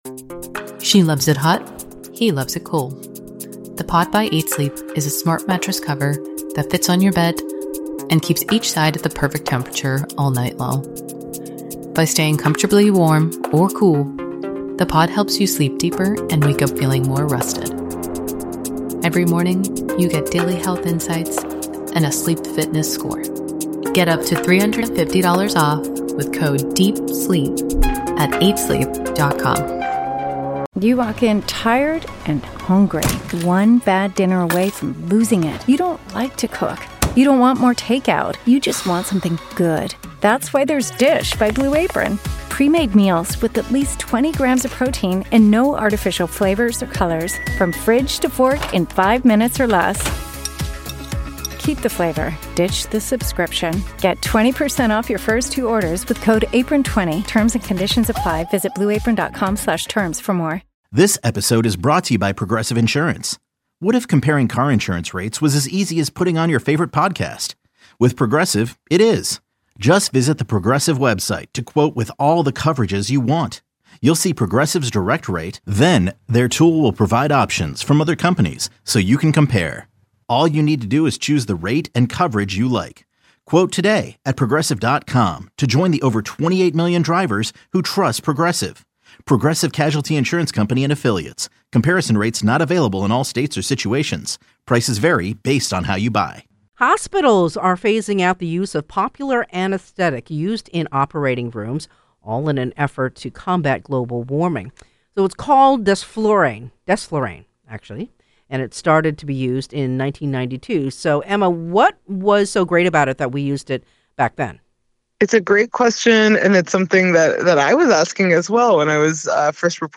Today, KCBS Radio anchor